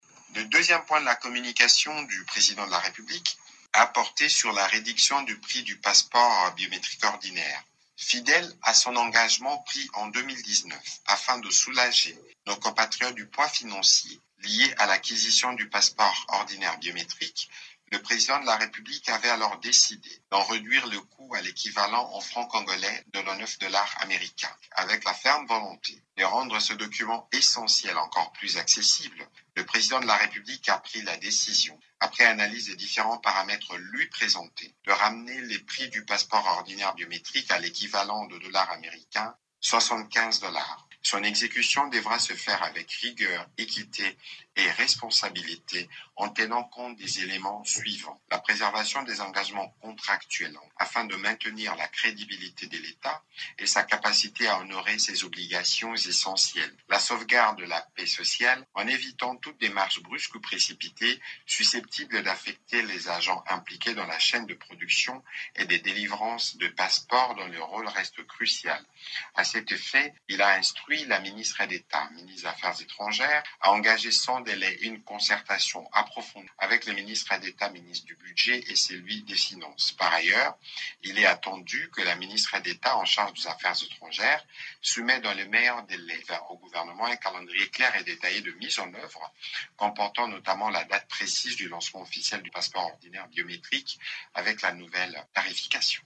Le porte-parole du gouvernement et ministre de la communication Patrick MUYAYA